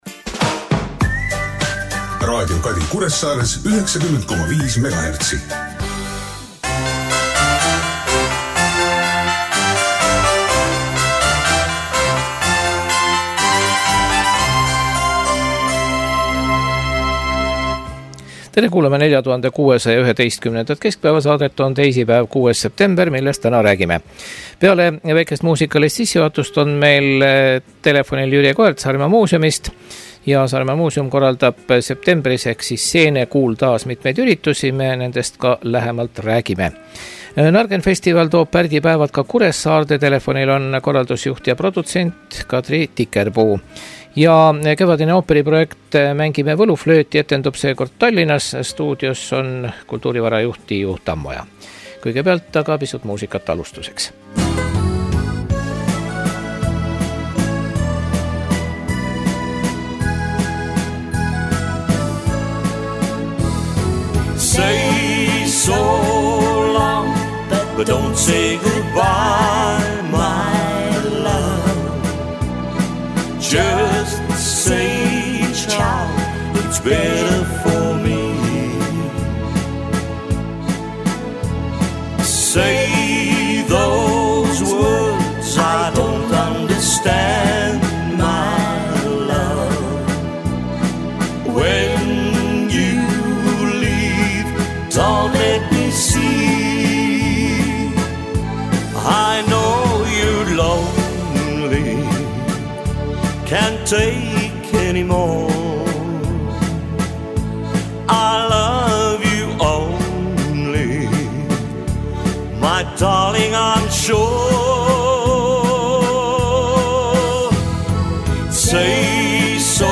Nargenfest toob Pärdi päevad Kuressaaree. Telefonil on Tõnu Kaljuste